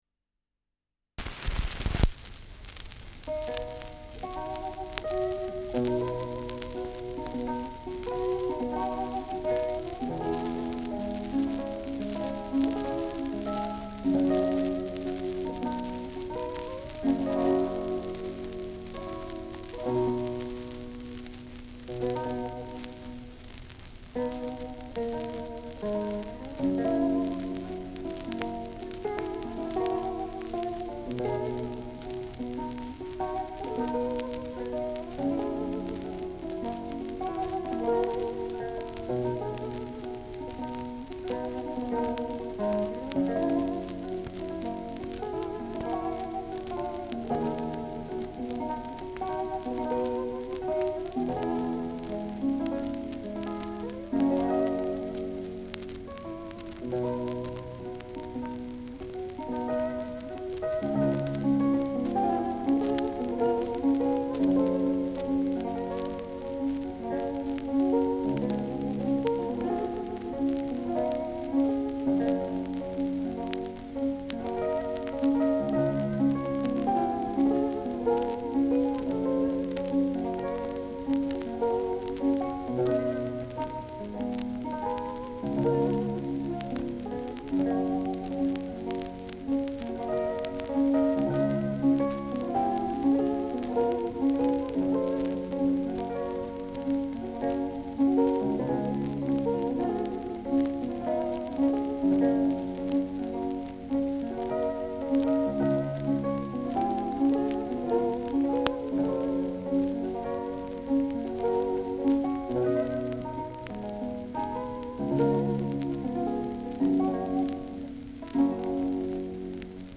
Schellackplatte
Grundknistern : Stärkeres Leiern : Abgespielt